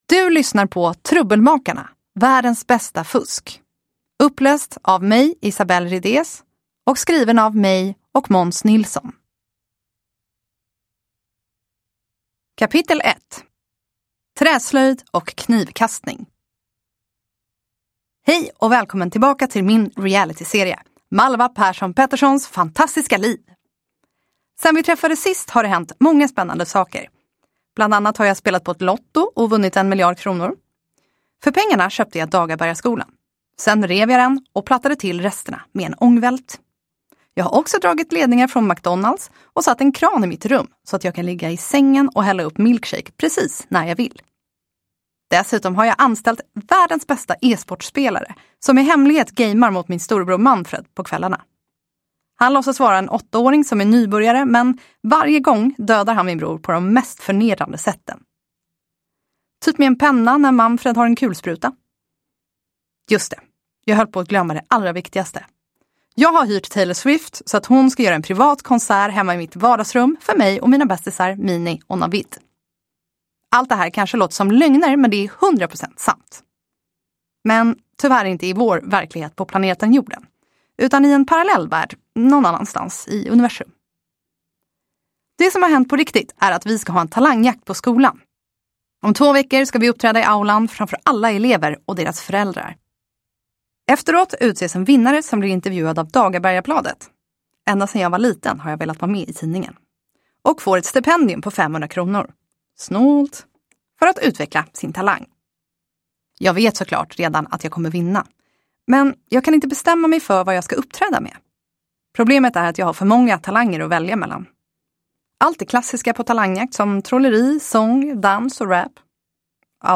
Världens bästa fusk – Ljudbok